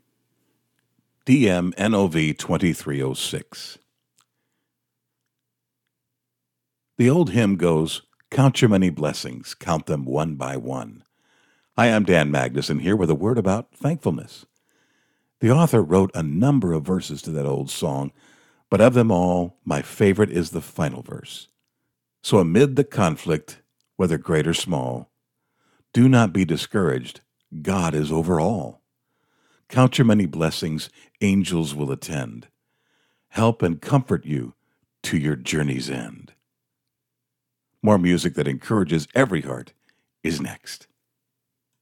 Voice guy with the makings of a nice studio.
The set up is EV Re20 with Lewitt Connect 6 which I like.